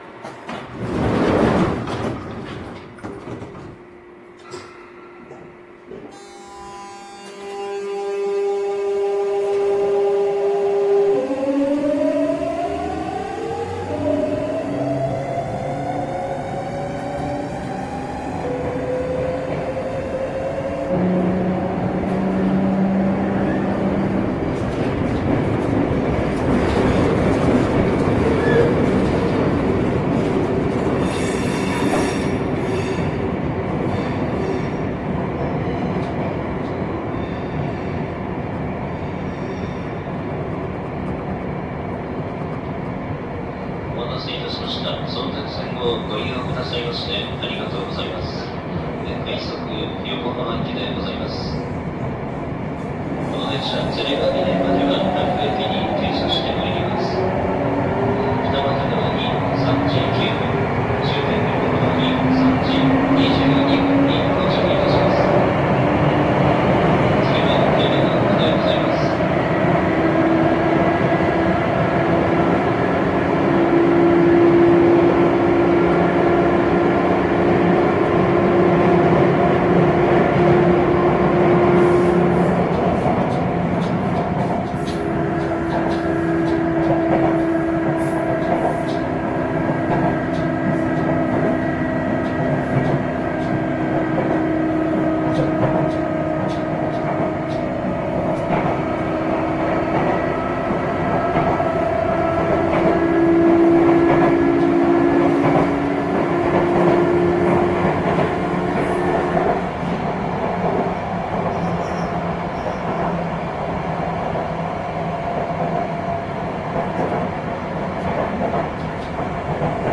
日立ＧＴＯ初期から後期に移行する際の中間的な期間にできた過渡期タイプのＶＶＶＦで、京王８０００系に代表されるような後期型に似ていますが、全体的に音が低いのが特徴です。
また、直角カルダンの影響もあり、特に中速域で独特な響きがします。
走行音（２ノッチ加速・8104） ←車両中央部で収録しました。
収録区間：いずみ野線 湘南台→ゆめが丘